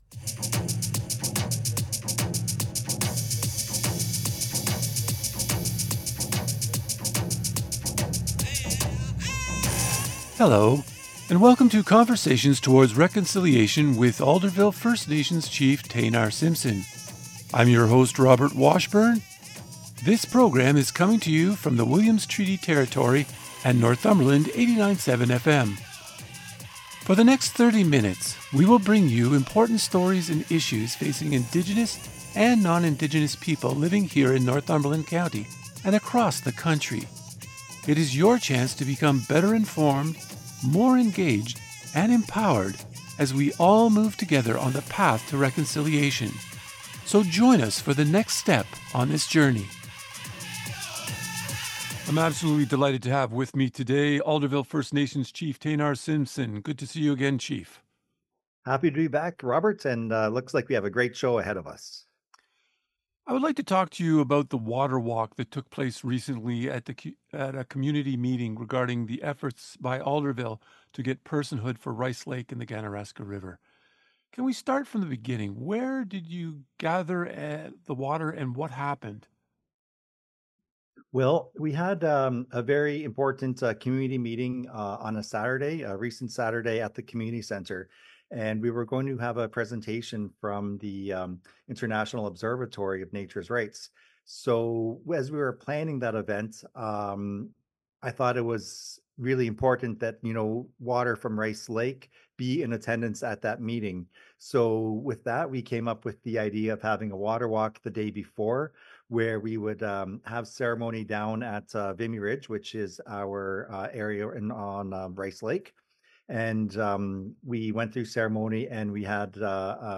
In this interview, Alderville First Nations Chief Taynar Simpson discusses several significant steps towards efforts to gain a new legal status for the two bodies of water in Northumberland County.